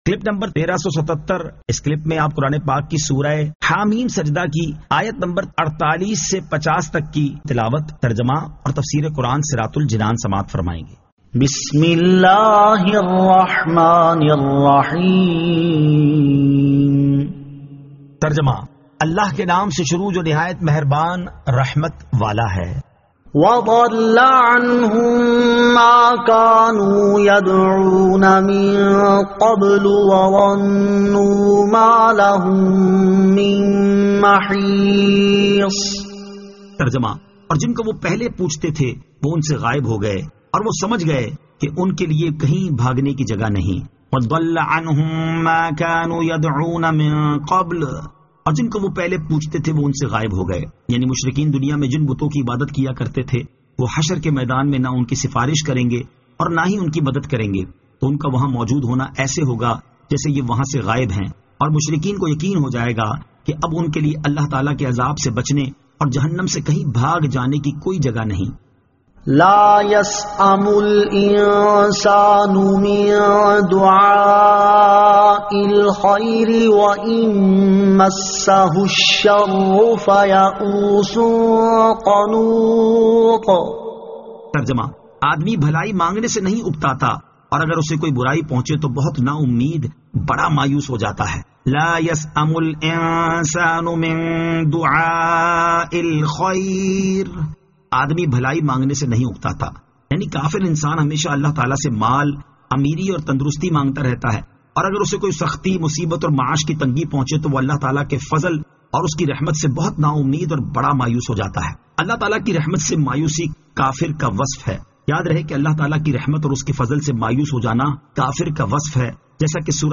Surah Ha-Meem As-Sajdah 48 To 50 Tilawat , Tarjama , Tafseer